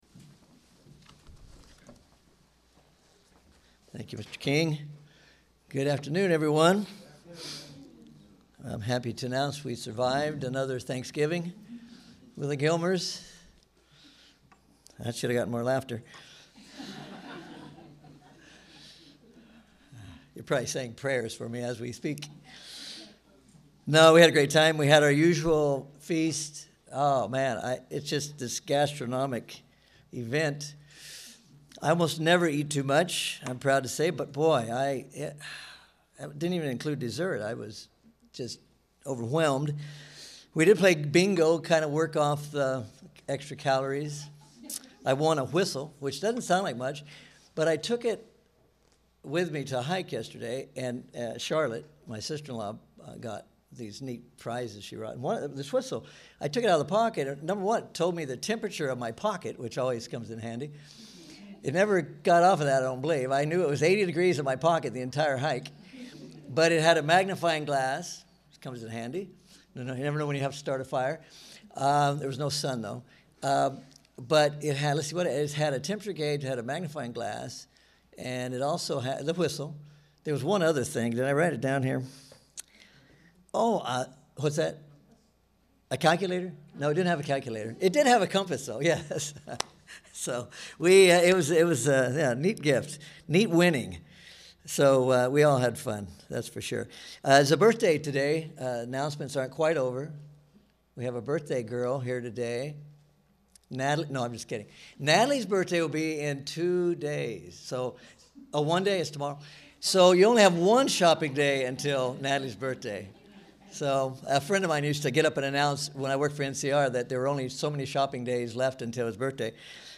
Given in Kingsport, TN